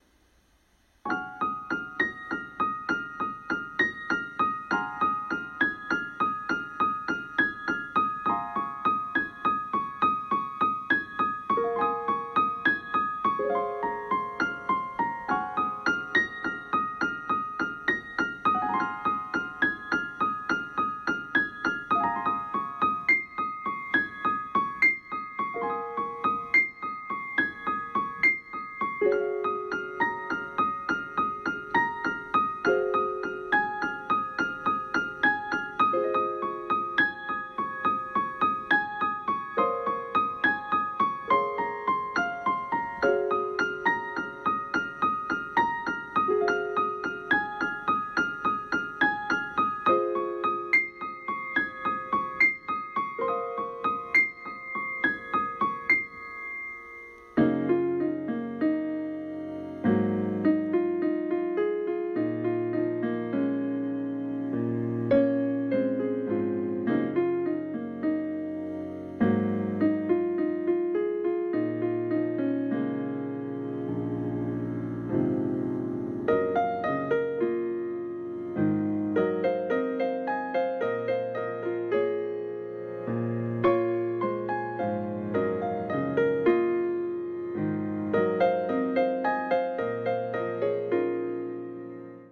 途中で終わってるのは、そこまでしか弾けないから！笑